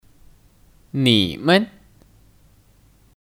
你们 Nǐmen (Kata benda) ： Kalian Contoh kalimat:你们是中国人吗 Nǐmen shì Zhōngguó rén ma？